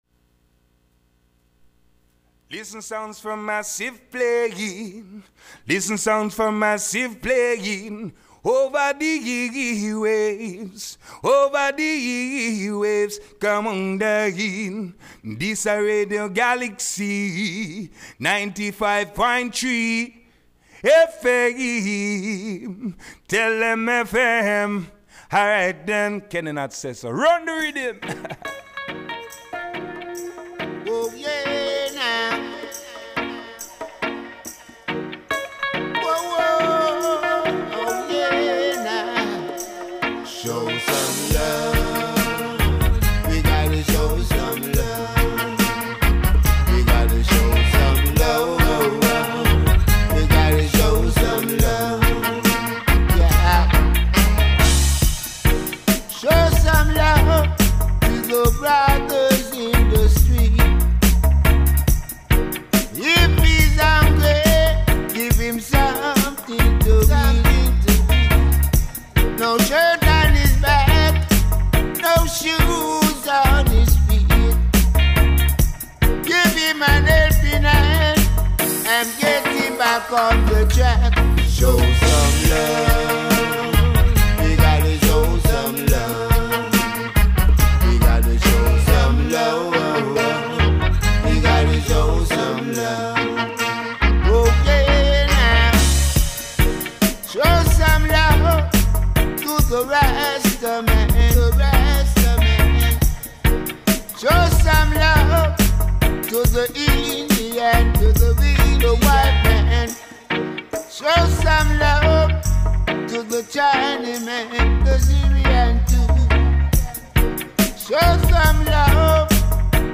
le REPLAY Retrouvez votre rendez-vous reggaephonique